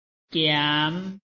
拼音查詢：【海陸腔】giam ~請點選不同聲調拼音聽聽看!(例字漢字部分屬參考性質)